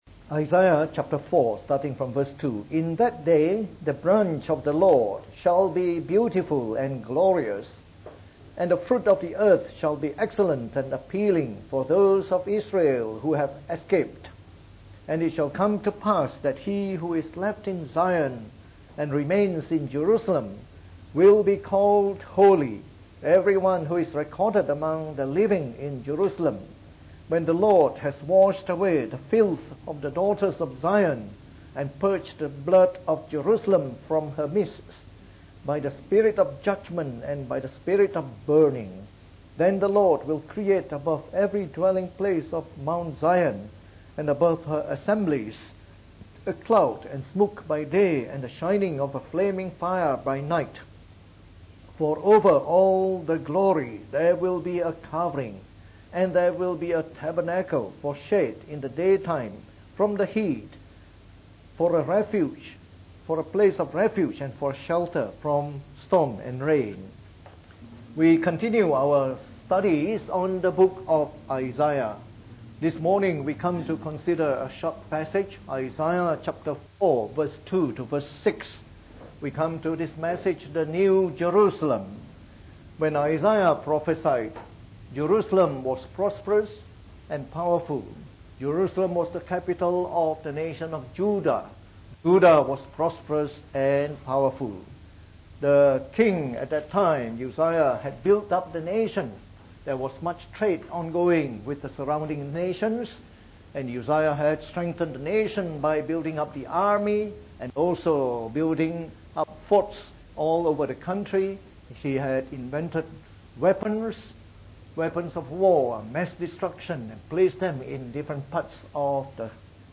From our new series on the book of Isaiah delivered in the Morning Service.